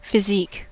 Ääntäminen
US
IPA : /fɪ.ˈziːk/